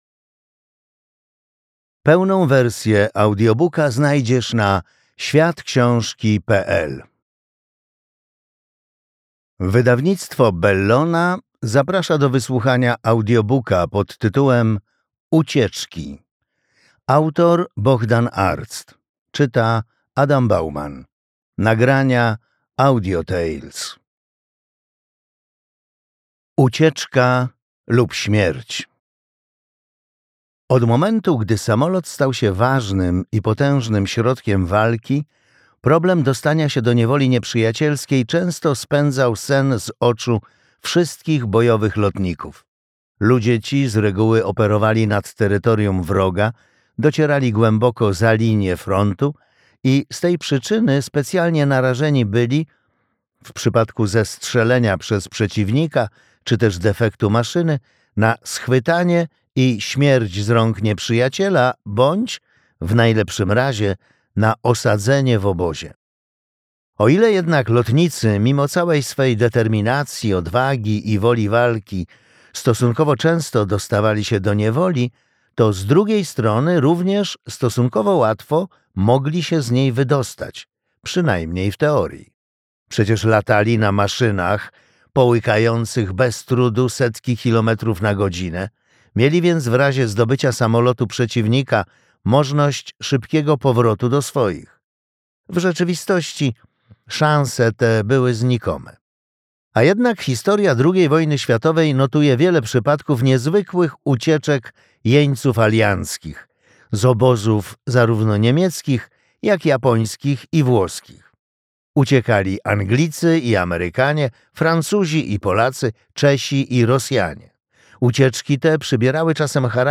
Ucieczki - Arct Bohdan - audiobook